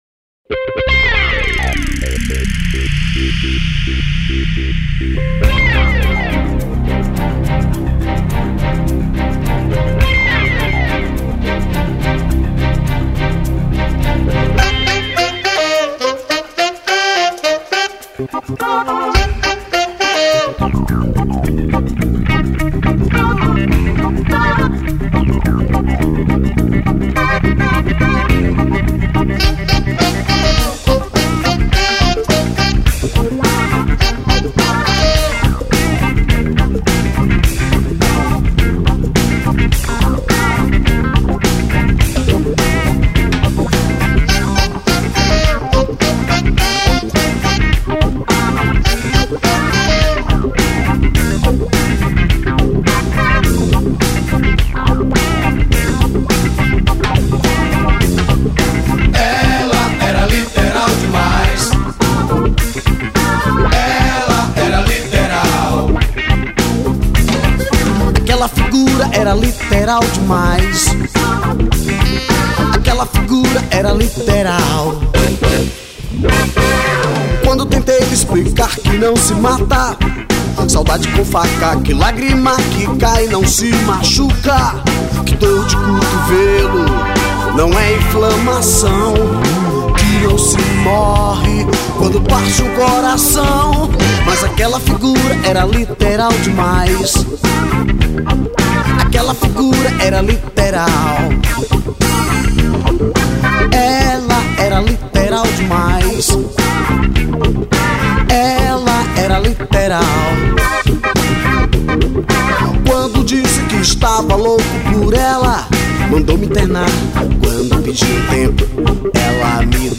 2965   04:49:00   Faixa: 8    Rock Nacional